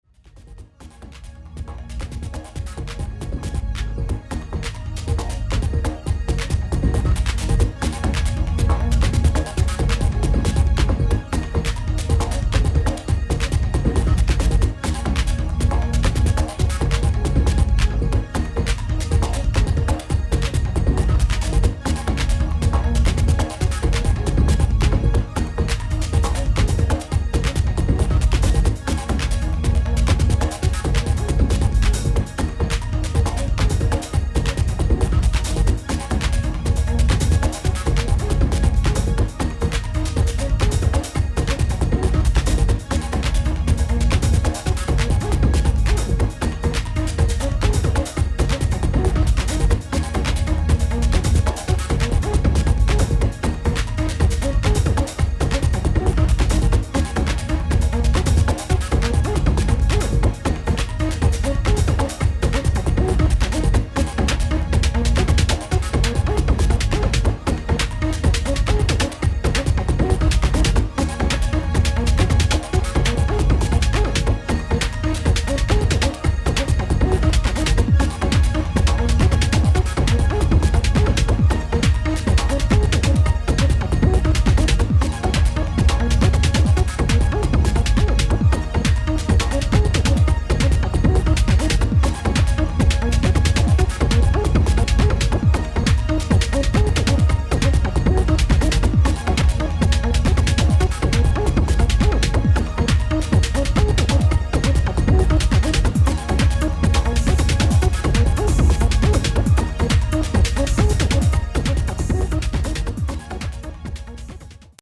Five tracks of classic melodic UK techno.